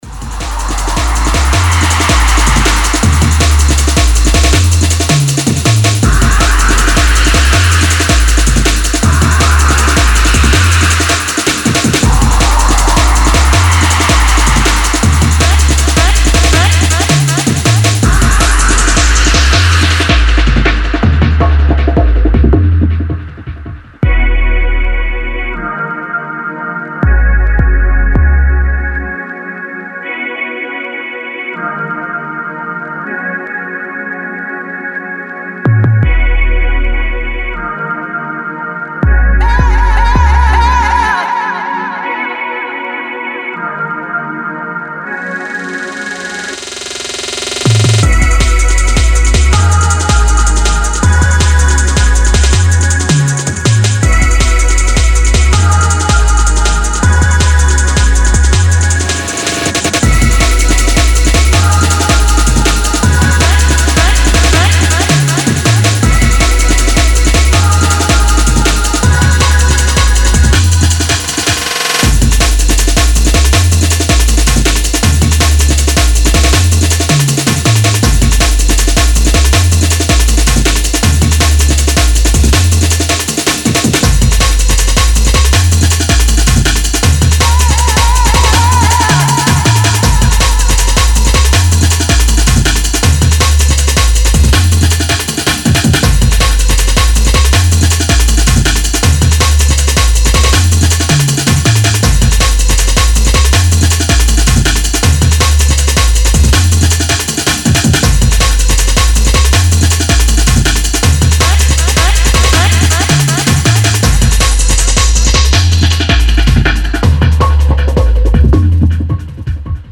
Jungle
a breakbeat powerhouse
Drum & Bass